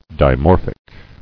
[di·mor·phic]